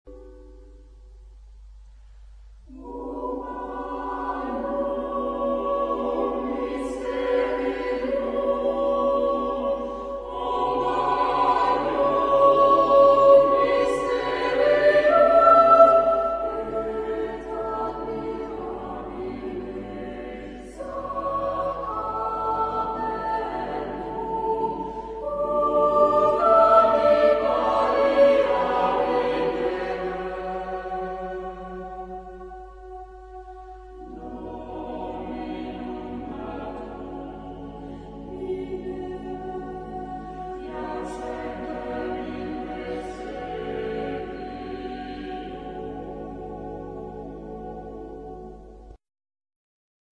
Genre-Style-Form: Motet ; Sacred
Type of Choir: SSATB  (5 mixed voices )
Tonality: B minor